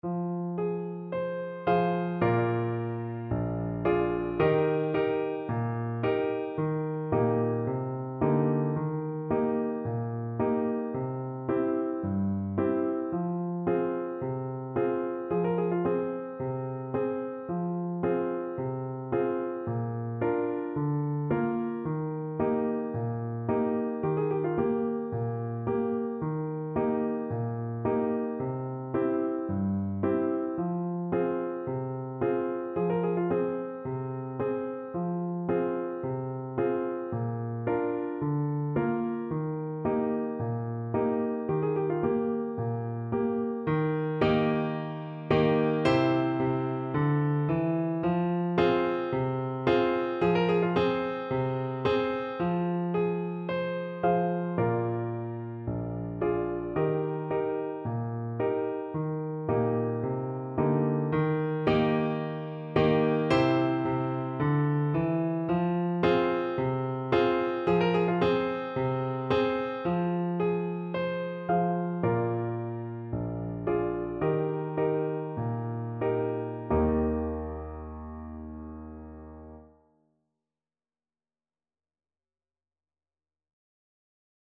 4/4 (View more 4/4 Music)
Allegro moderato =c.110 (View more music marked Allegro)
Traditional (View more Traditional Clarinet Music)
Portuguese